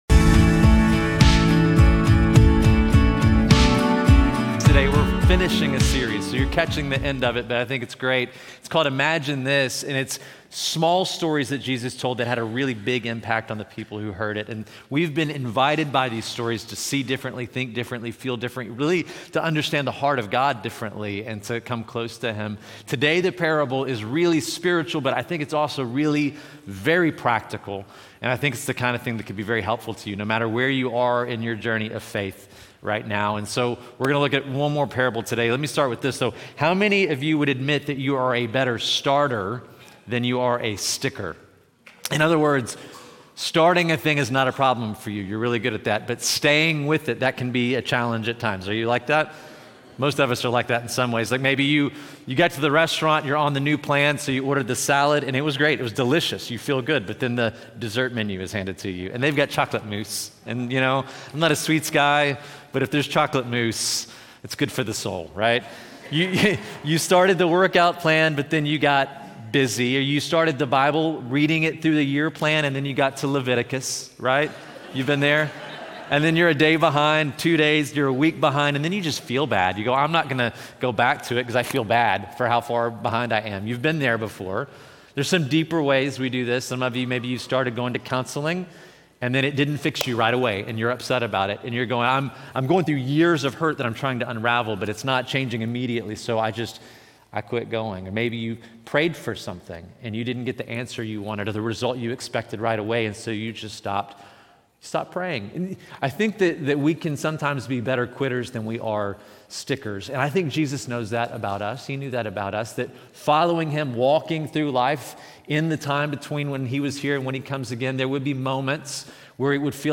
This sermon on The Parable of the Persistent Widow teaches that prayer is not about persuading a reluctant God, but about forming a persevering faith that stays aligned with Him even when heaven feels silent.